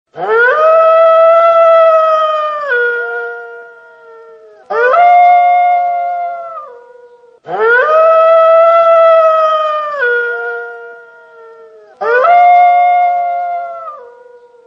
Wolf Howl klingelton kostenlos
Kategorien: Tierstimmen